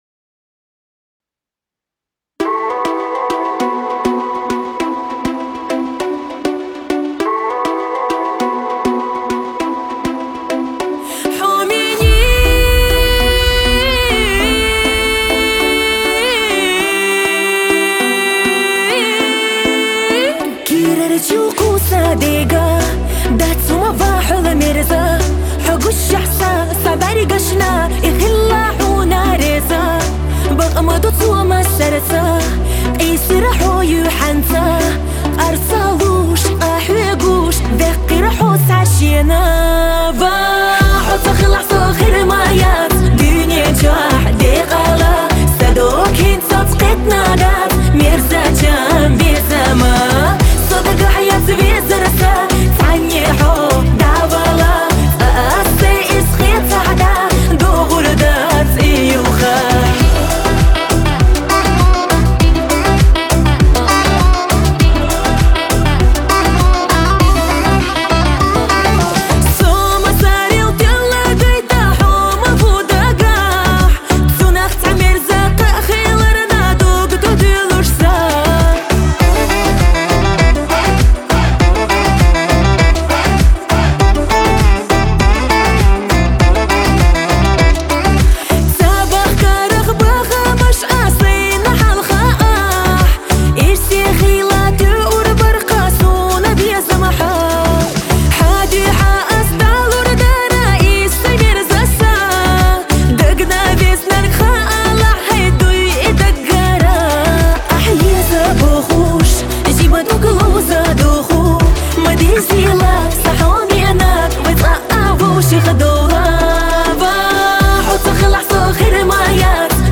Красивая современная чеченская песня 2025